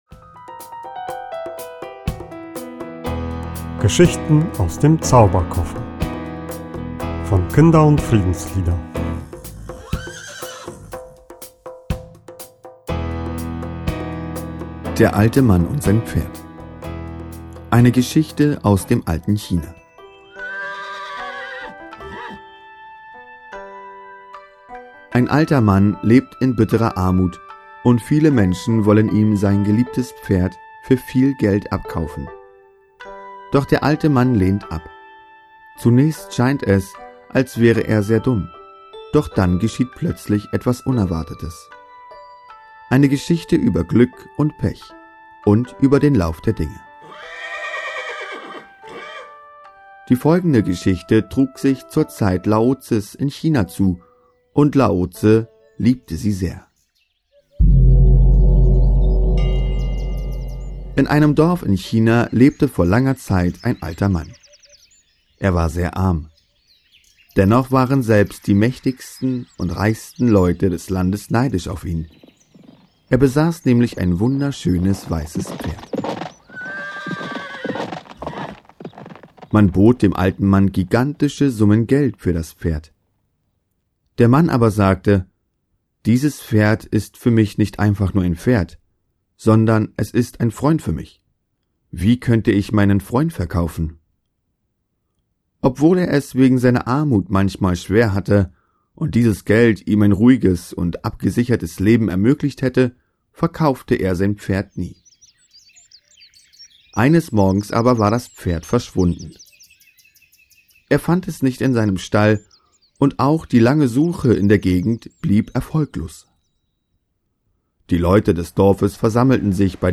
Art: Kindergeschichte, chinesische Geschichte, kurze Gute-Nacht-Geschichte, Erzählgeschichte, Geschichten zum Vorlesen
Video & Hörbuch: